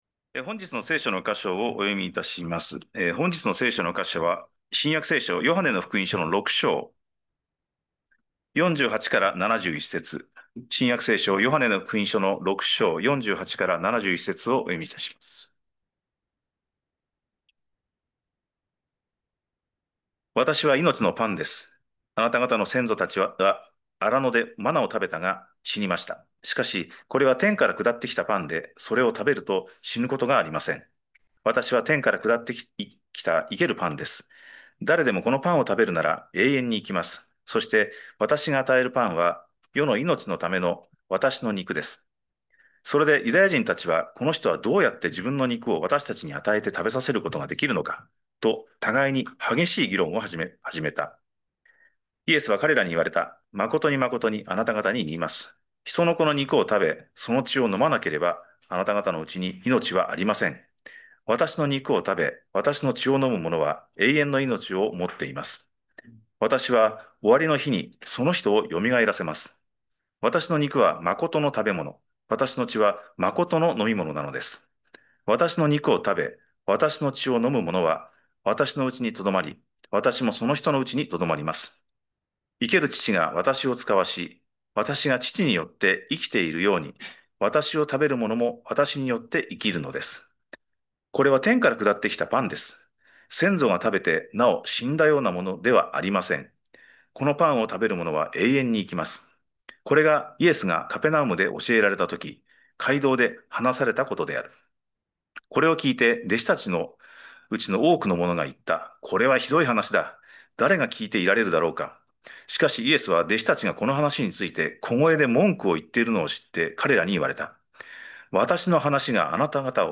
2025年9月28日礼拝 説教 「人の子の肉を食べなさい」 – 海浜幕張めぐみ教会 – Kaihin Makuhari Grace Church